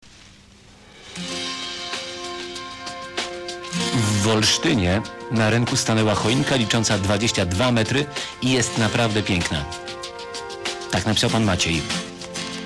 O naszej 22 metrowej choince wspomniano nawet na antenie Programu 3 Polskiego Radia!